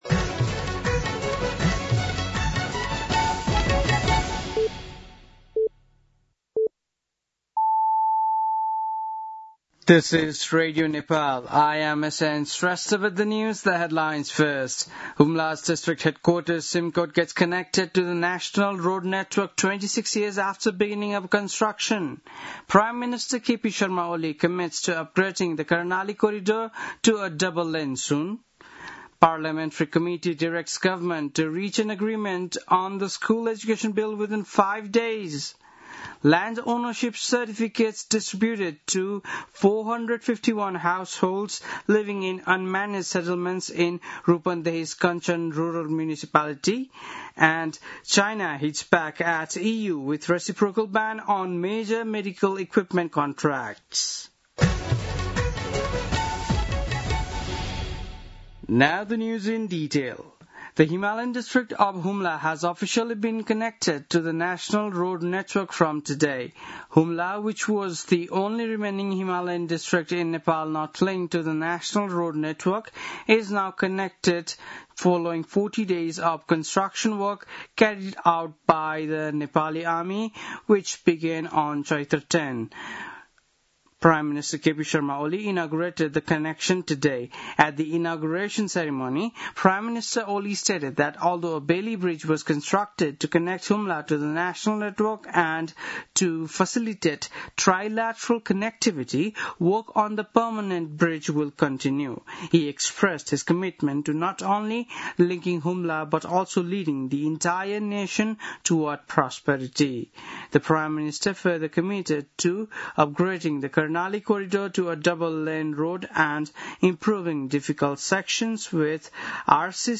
बेलुकी ८ बजेको अङ्ग्रेजी समाचार : २२ असार , २०८२
8.-pm-english-news-.mp3